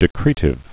(dĭ-krētĭv)